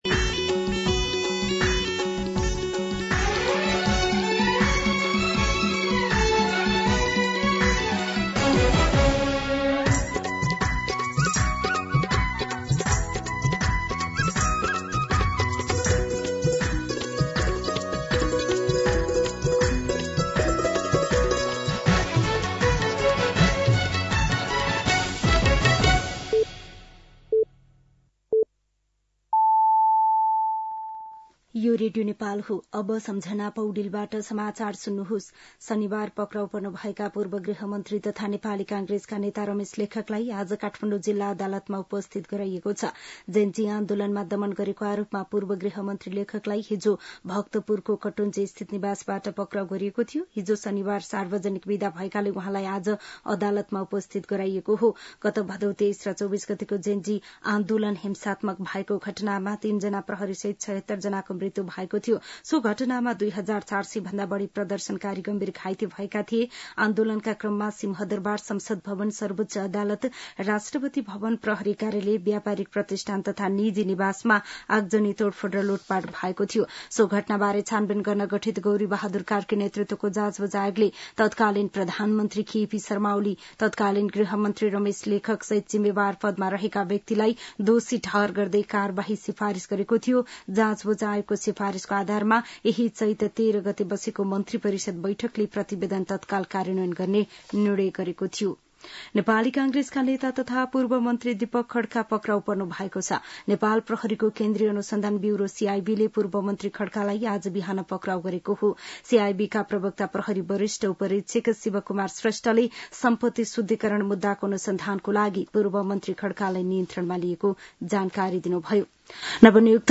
An online outlet of Nepal's national radio broadcaster
दिउँसो १ बजेको नेपाली समाचार : १५ चैत , २०८२